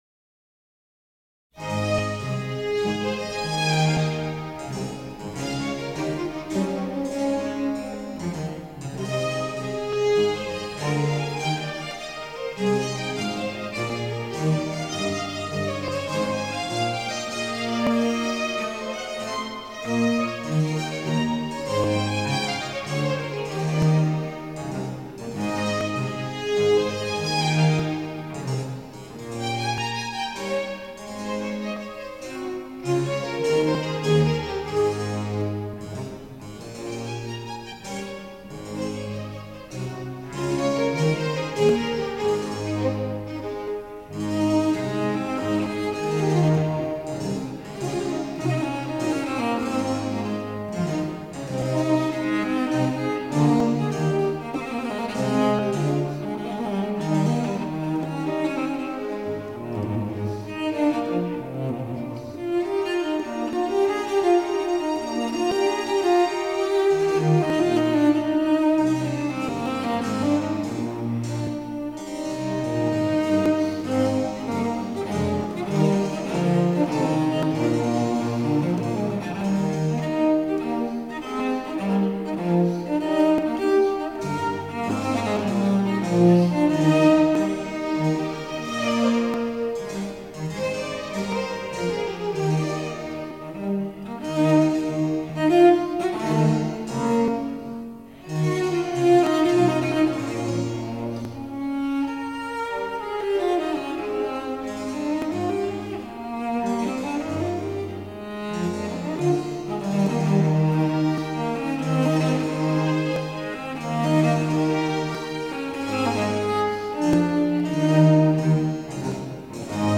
Leonardo Leo-Concerto for cello & strings No.1 in A major Jeanne Lamon (cond) Tafelmusik Baroque Orchestra Anner Bylsma (cello) 1.